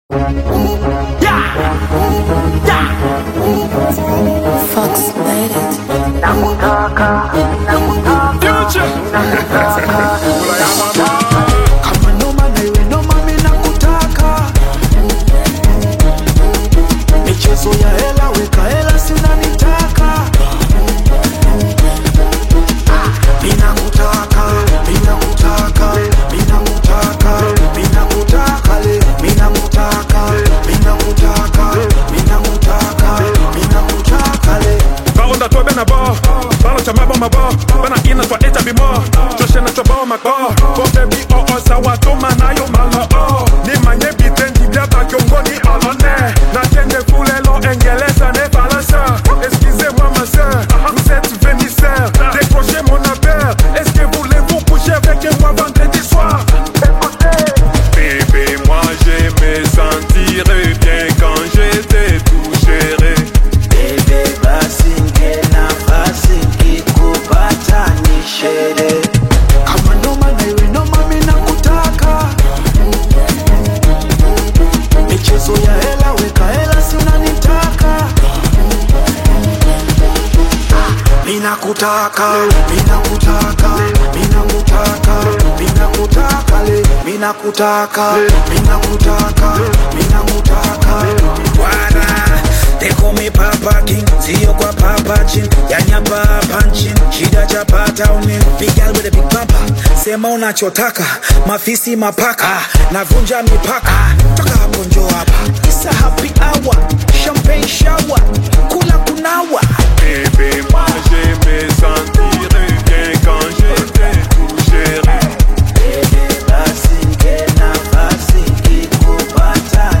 dynamic Afro-Urban/Bongo Flava collaboration